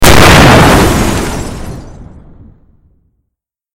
Download Free Explosion Sound Effects
Explosion